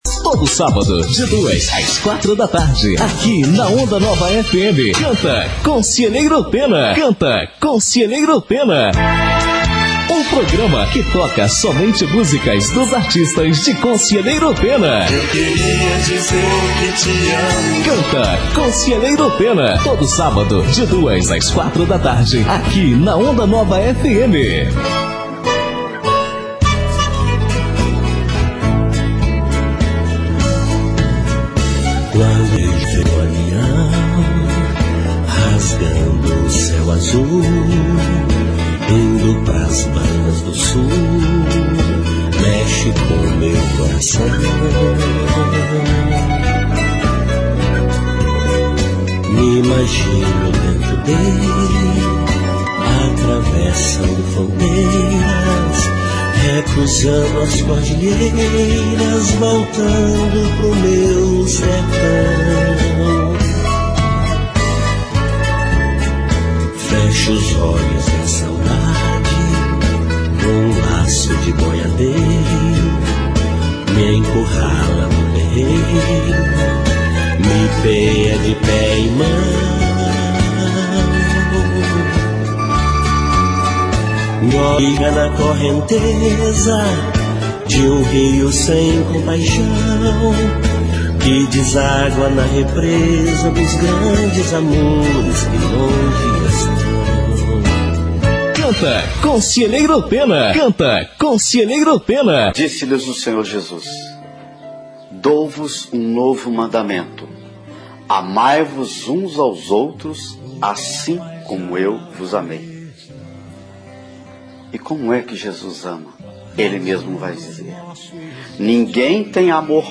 Caso venha ouvir a gravação ou assistir no Youtube, no Podcast, observe que uma das atrizes chorou de verdade quando o texto exigiu este comportamento.
Os ouvintes aplaudiram.